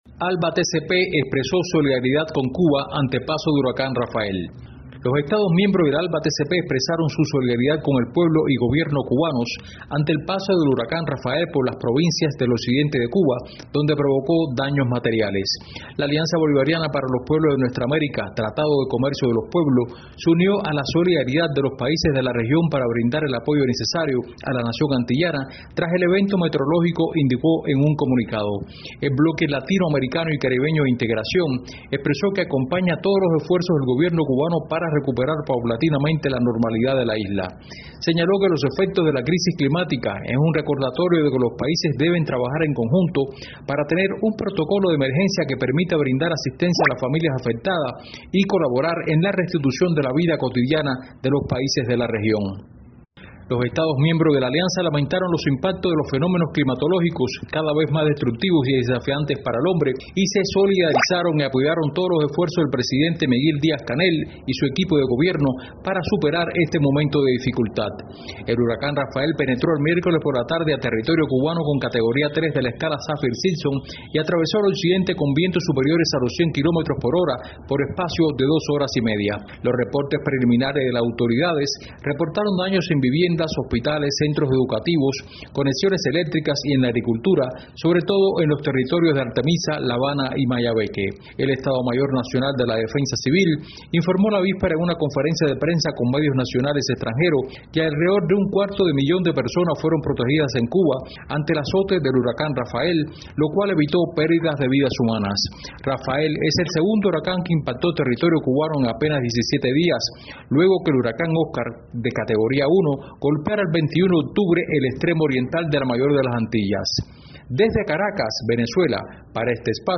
desde Caracas